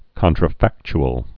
(kŏntrə-făkch-əl)